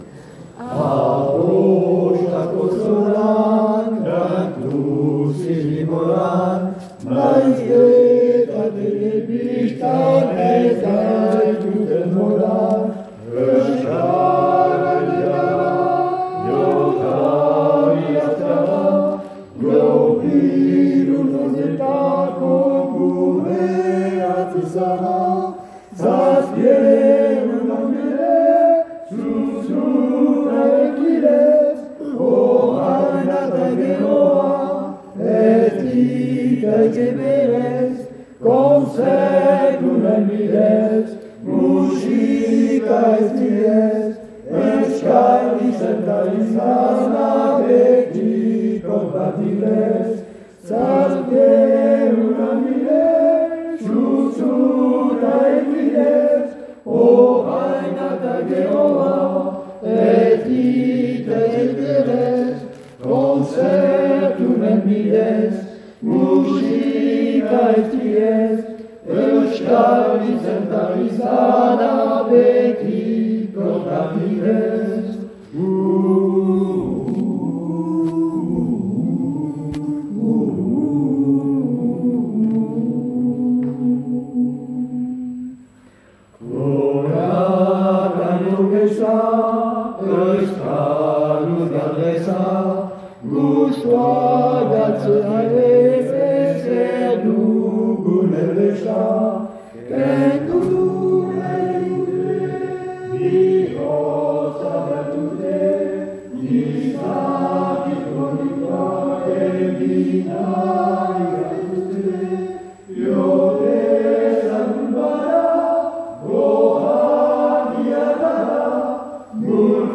Pour ceux qui vous sont proposés à l'écoute, vous voudrez bien excuser les parasites et la qualité inégale des enregistrements, tous réalisés en public.
Arrosako zolan (3.01 Mo) : à 2 voix d'hommes Jo Maris Euskadi XXème